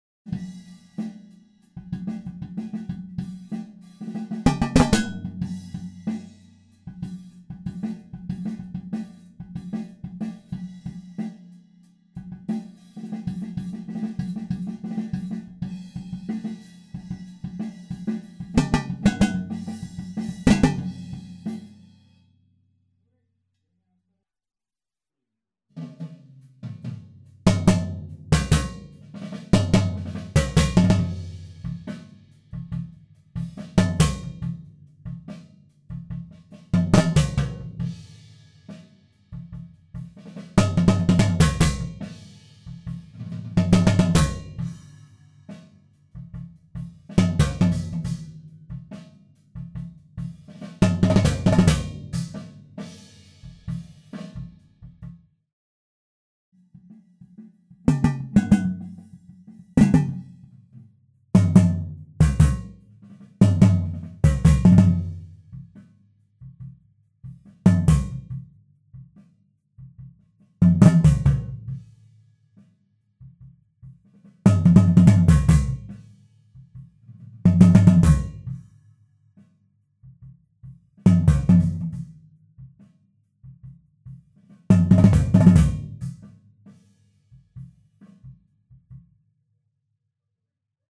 Přechody a kotel, respektive tomy a floortom
Na začátku tak jak bylo nahráno „na rovno“, ve druhém opakování zpracováno gatem, kompresorem a EQ. Zajímavý zvuk plážového míče, co řikáte?